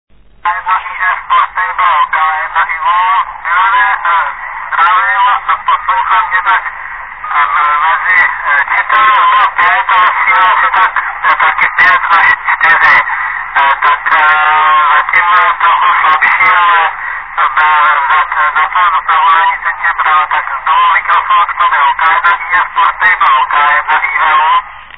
Zařízení Traper o výkonu asi 10W.
Bohužel jsem měl sebou jen jednoduchý digitální záznamník Přesto z nahrávky lze zjistit, že spojení nemělo vadu: